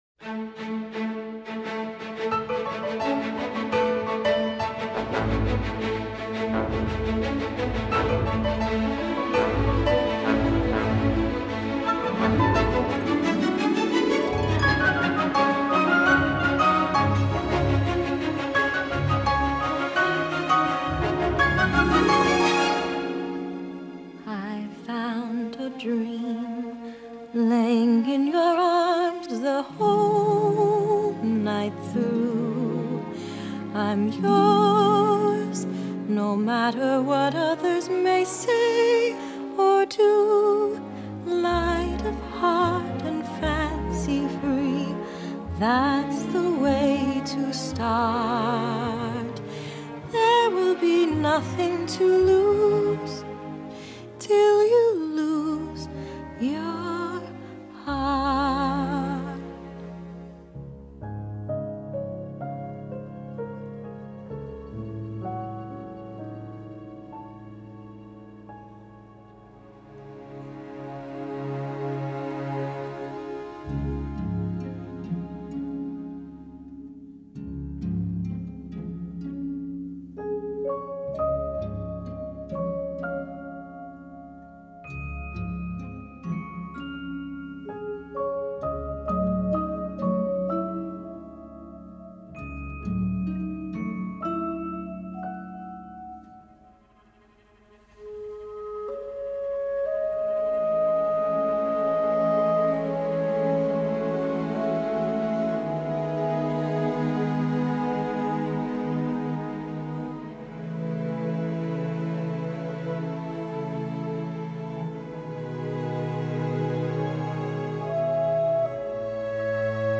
2011   Genre: Soundtrack   Artist